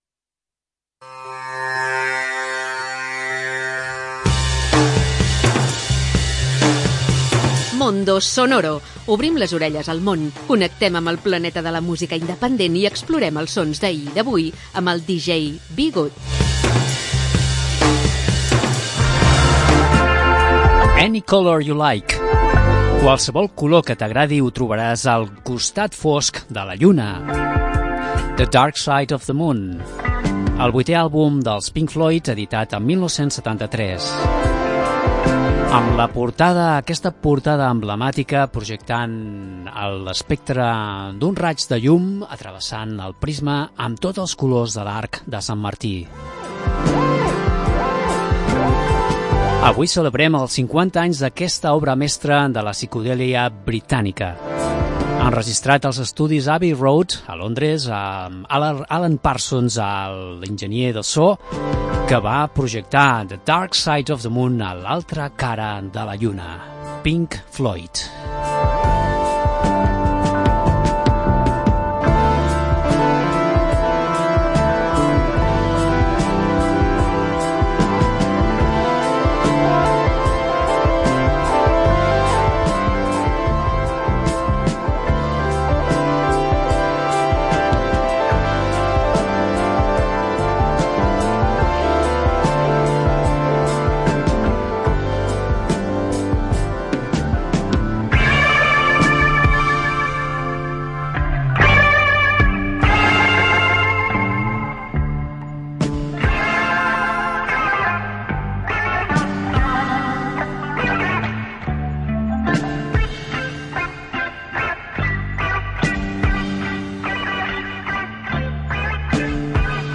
Una selecció de música amb esperit independent